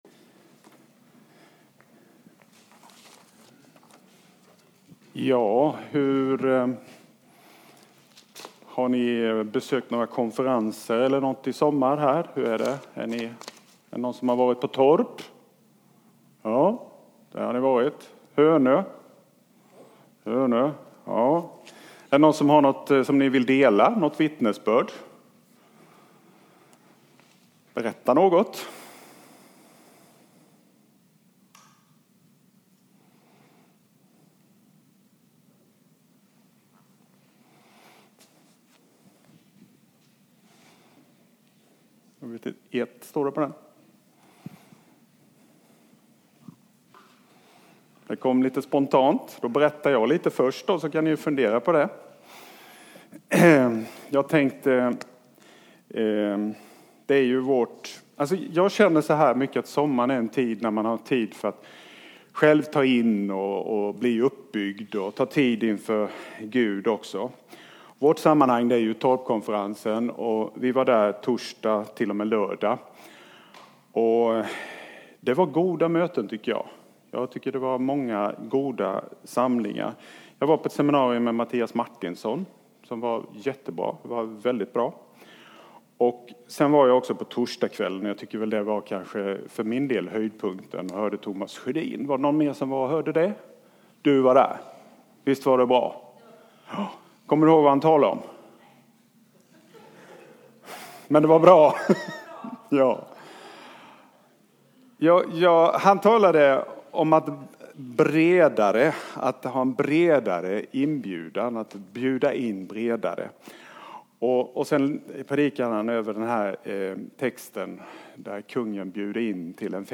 Predikan
A predikan from the tema "Sommargudstjänster 2018."
Från Tema: "Sommargudstjänster 2018"